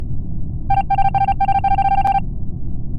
Among Us Voted Out Rejected Sound Effect Free Download